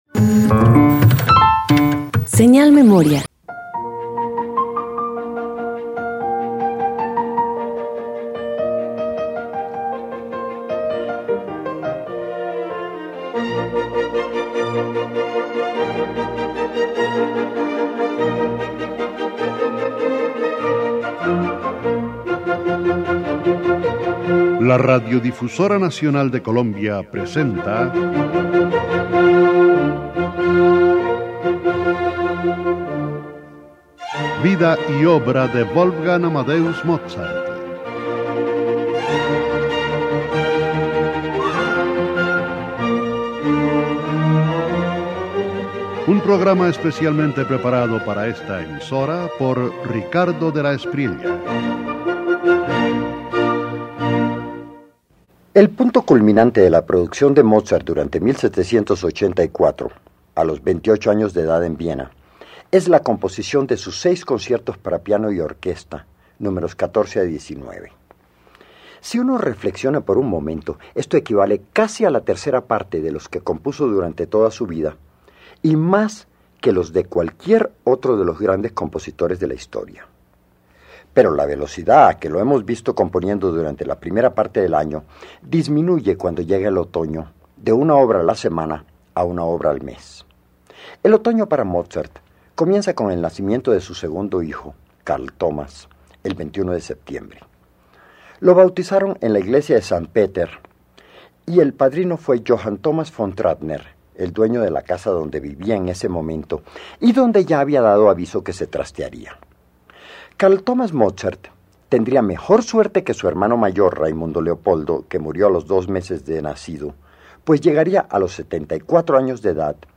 Radio colombiana
Concierto para piano y orquesta